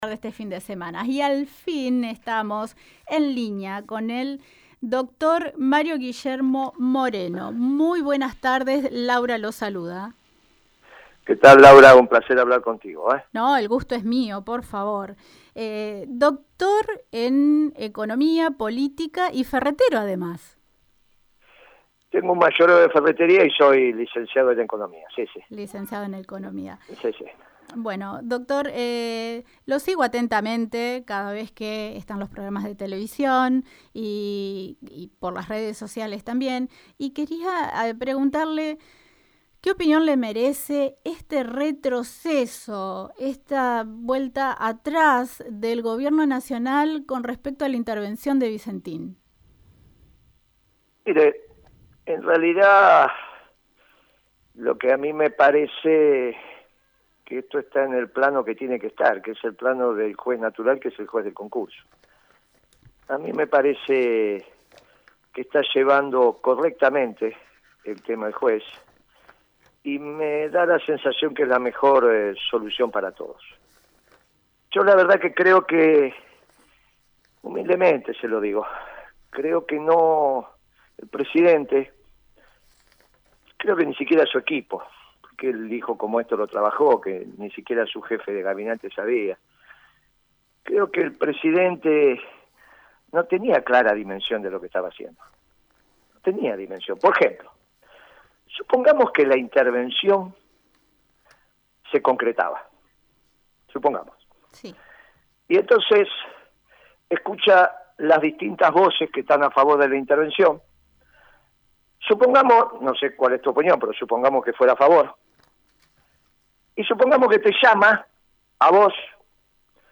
En dialogo con Radio EME, el economista Mario Guillermo Moreno analizó el actuar del gobierno de la Nación en la intervención de la empresa Vicentín.